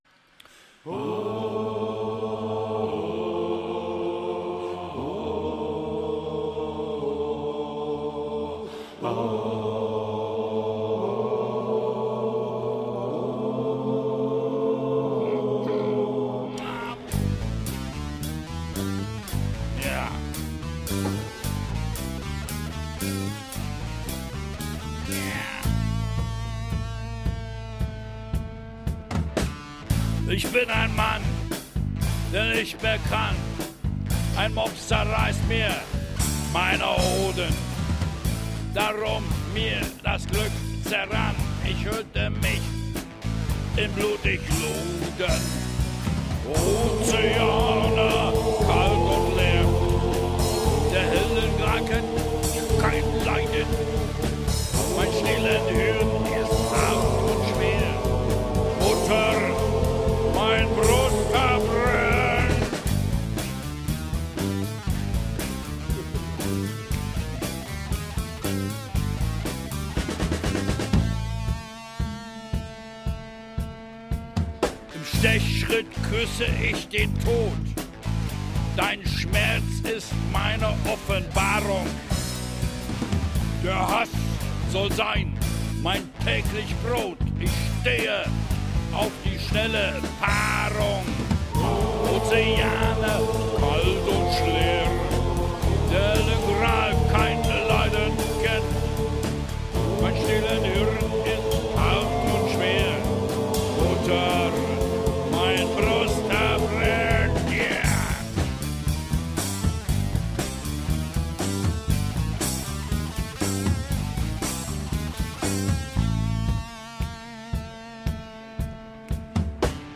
Gesang
Gitarre
Schlagzeug
Bass